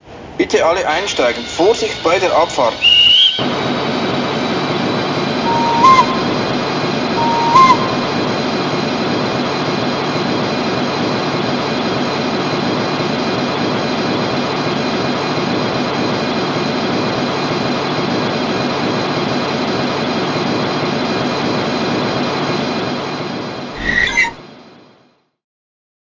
• Lokomotive mit umfangreichen Licht- und Soundfunktionen für ein vielfältiges Spielerlebnis
Digitalfunktionen: Fahrsound mit umfangreichen Soundfunktionen, Fernlicht, Führerstandsbeleuchtung, Schweizer Lichtwechsel, dieses Fahrzeug mit mfx Decoder meldet sich an einer mfx fähigen Digitalzentrale selbst an zum Beispiel an der Mobile Station von Märklin, unterstützt das DCC Datenformat